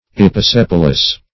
Search Result for " episepalous" : The Collaborative International Dictionary of English v.0.48: Episepalous \Ep`i*sep"al*ous\, a. [Pref. epi- + sepal.]